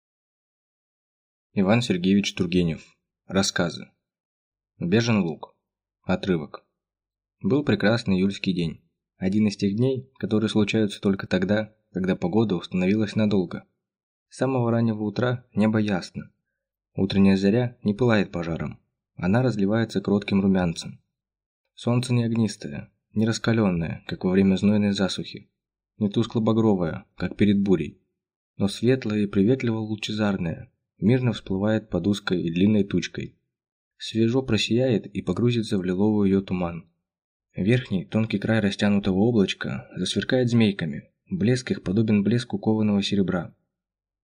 Aудиокнига
Читает аудиокнигу